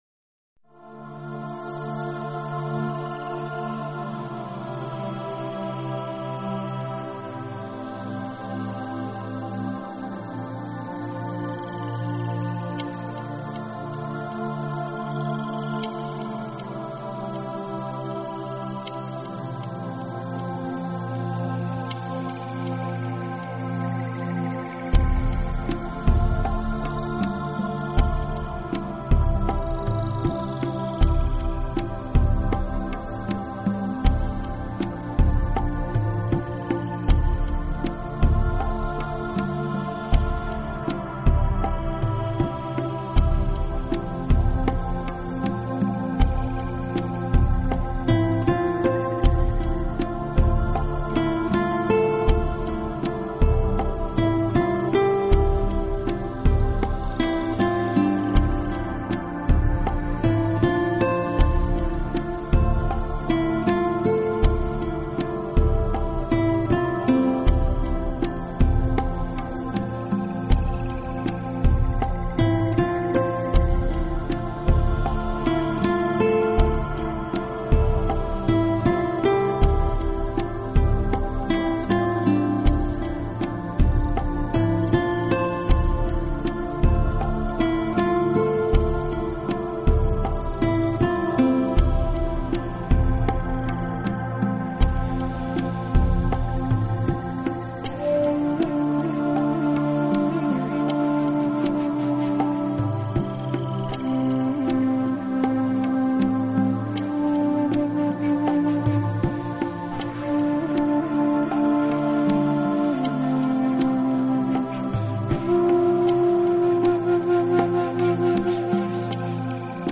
音乐流派：New Age | Instrumental